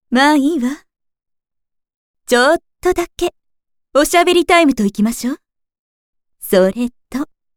ボイス
性別：女